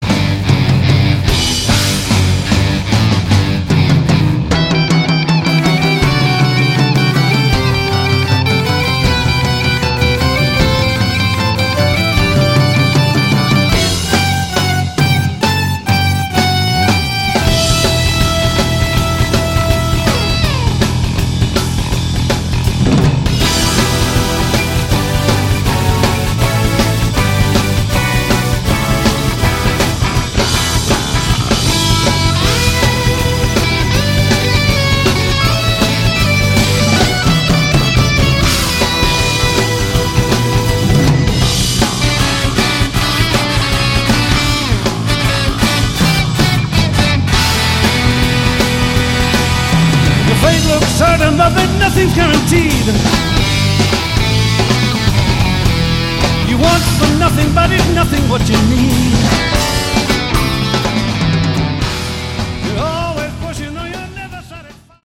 Category: Prog Rock
bass, vocals
drums
guitars
keyboards